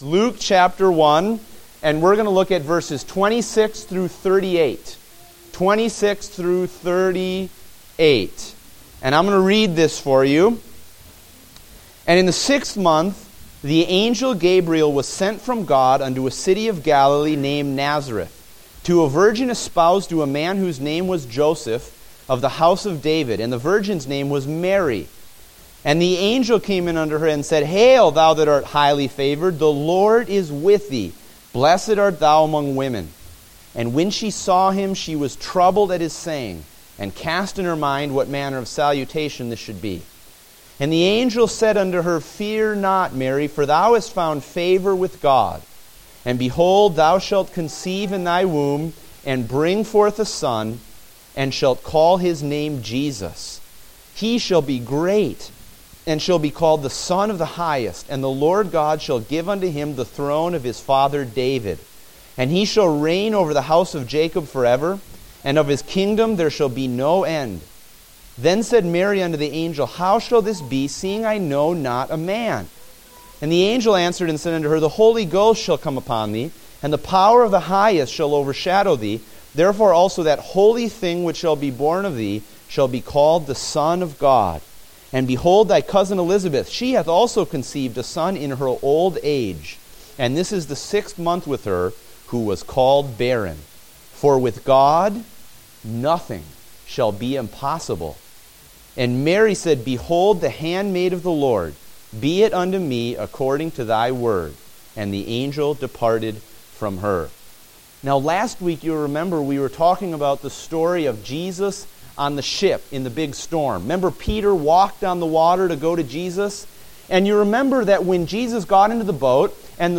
Date: December 20, 2015 (Adult Sunday School)